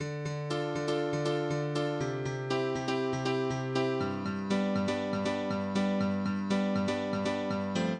ITA Piano Riff D-G-C-F.wav